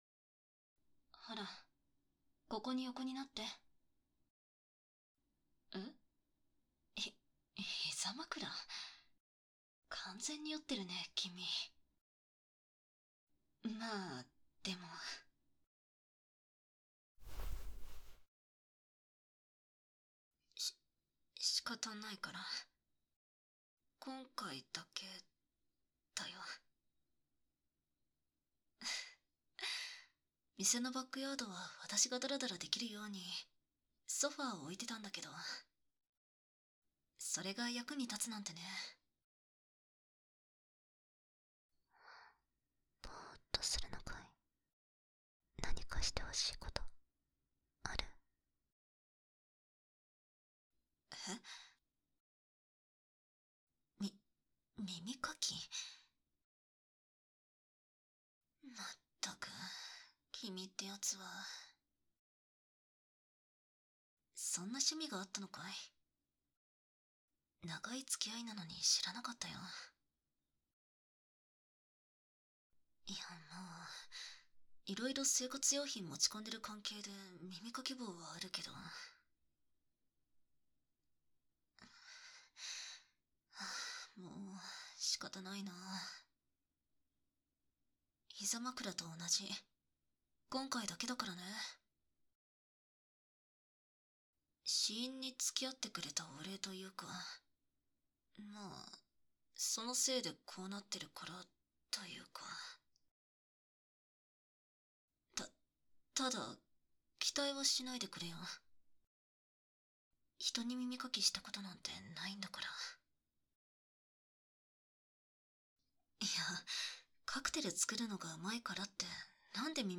【シェイカー/耳かき】バーテンダー ‐お酒と耳かきと…‐
掏耳 环绕音 ASMR